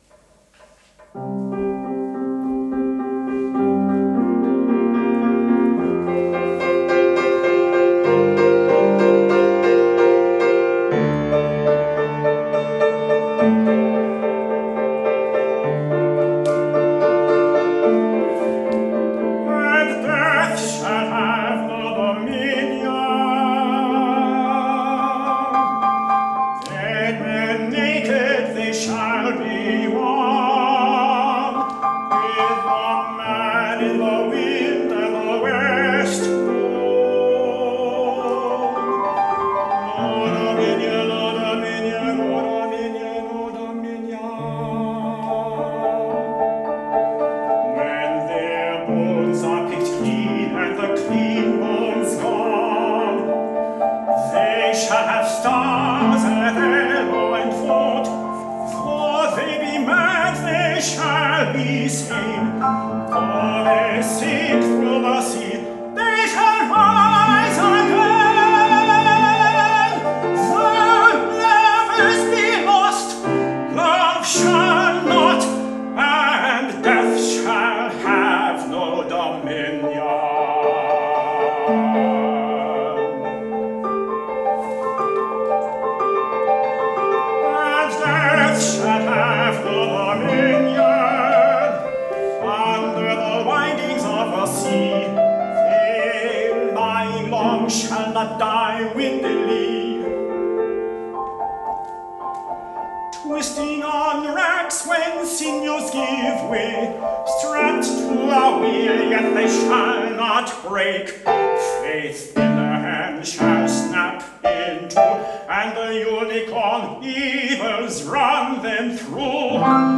for Tenor and Piano (2011)
Tempi are slow, and minor keys are prevalent.